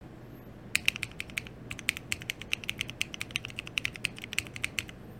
sounds pretty good but isn’t there supposed to be a snapping chunk in the middle of those? seems kind of hollow